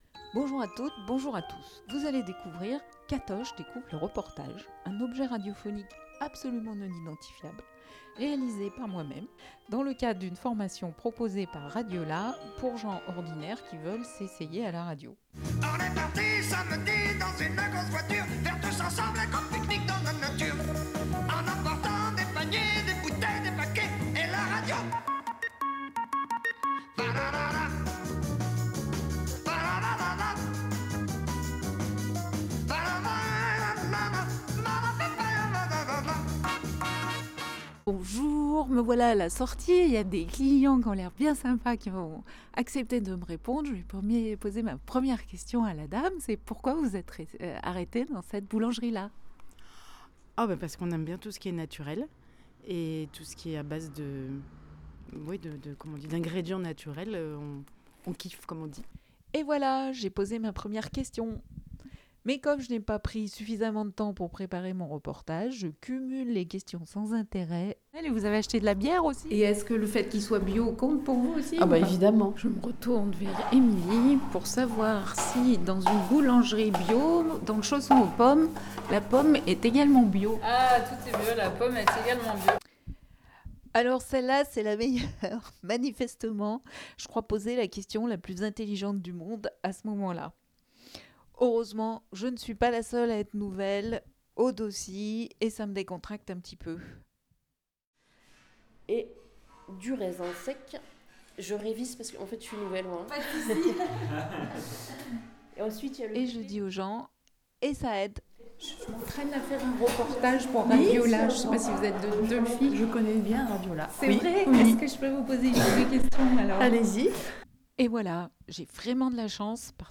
7 janvier 2020 16:21 | ateliers, Interview, reportage